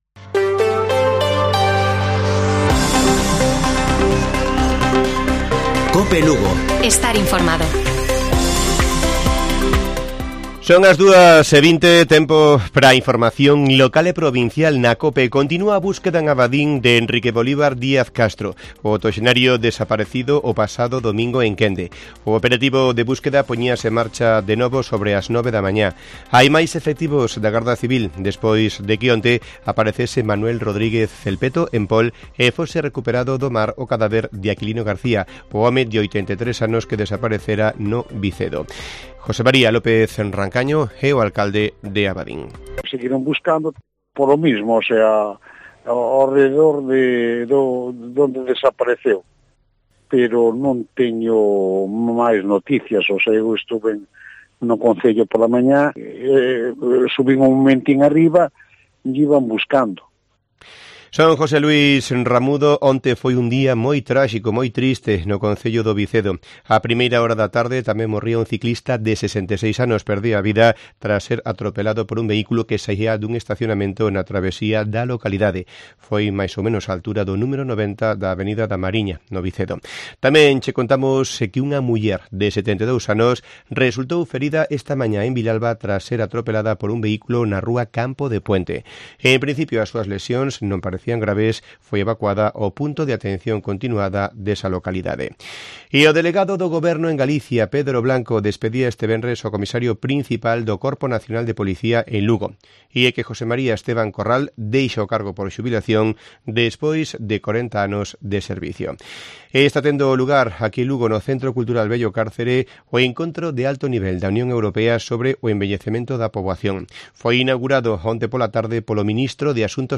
Informativo Mediodía de Cope Lugo. 08 de septiembre. 14:20 horas